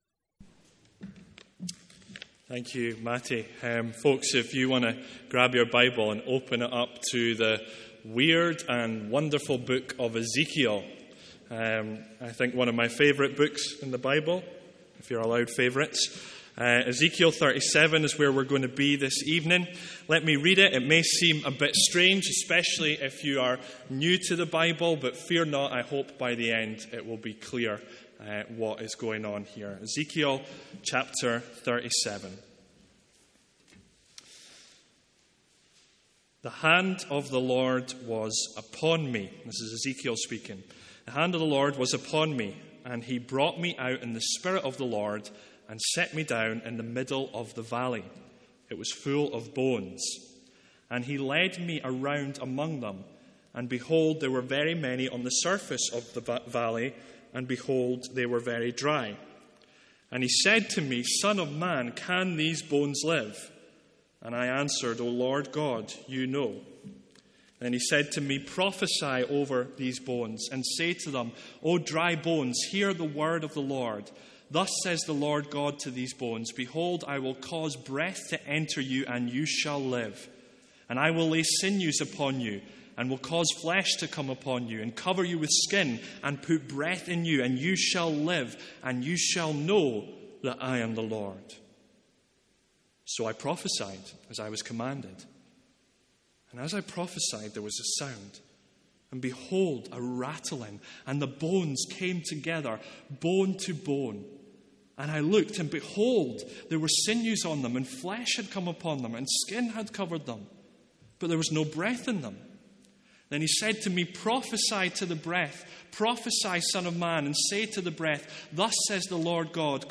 A one off sermon from Ezekiel 37:1-14.